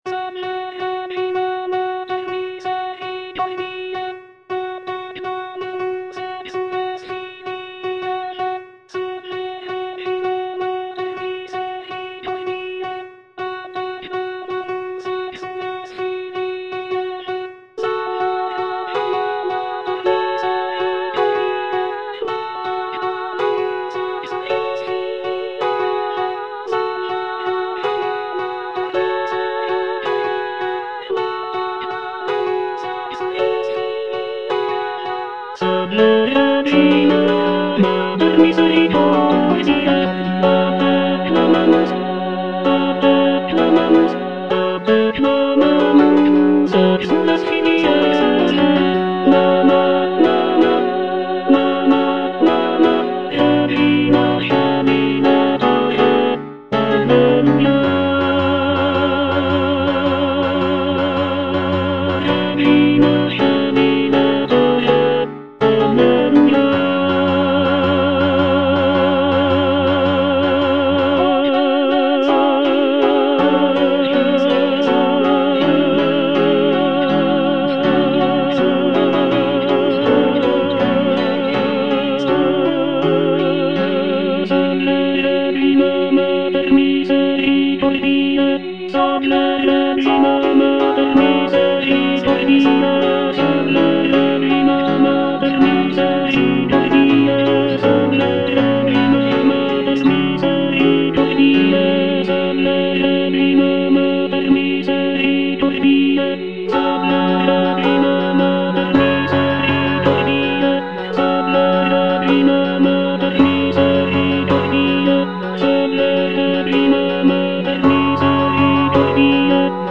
Tenor I (Emphasised voice and other voices) Ads stop